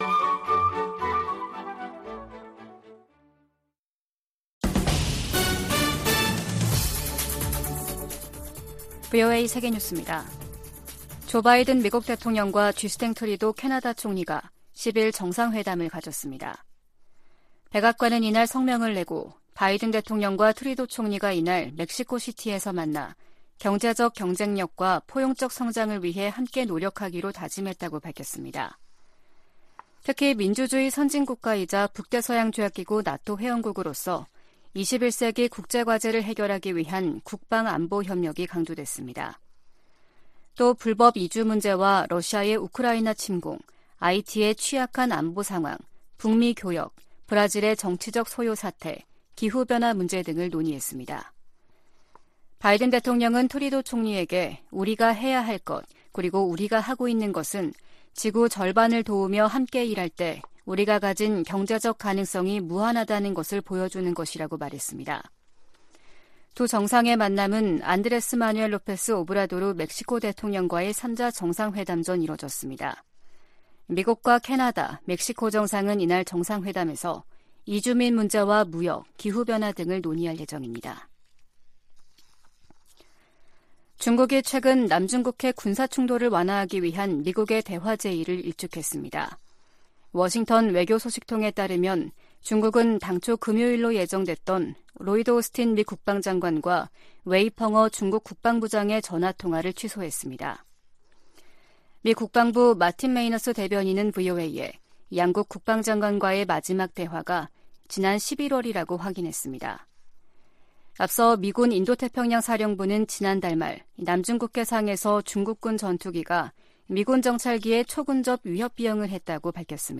VOA 한국어 아침 뉴스 프로그램 '워싱턴 뉴스 광장' 2023년 1월 11일 방송입니다. 미 국무부는 방한 중인 국무부 경제 차관이 미국의 인플레이션 감축법(IRA)에 대한 한국의 우려에 관해 논의할 것이라고 밝혔습니다. 한국 군 당국은 대북 확성기 방송 재개 방안을 검토하고 있는 것으로 알려졌습니다.